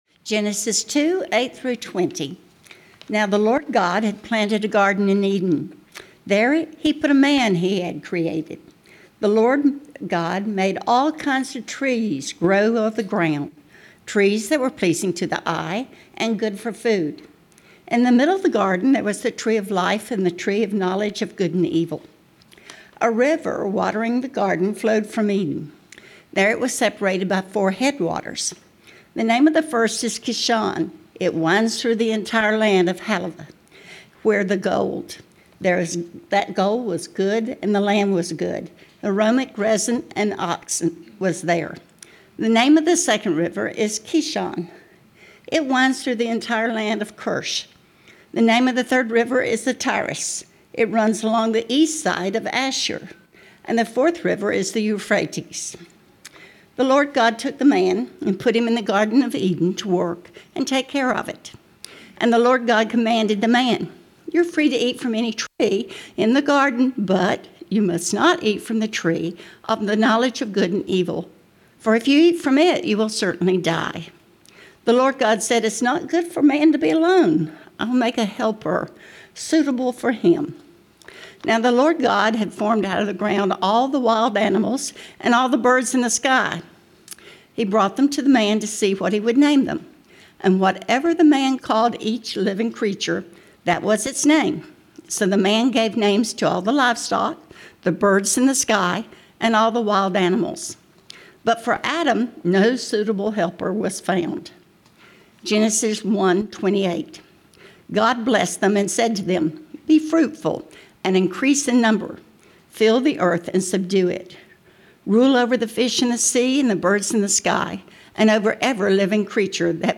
September 7, 2025 Sermon Audio - Christ United Methodist Church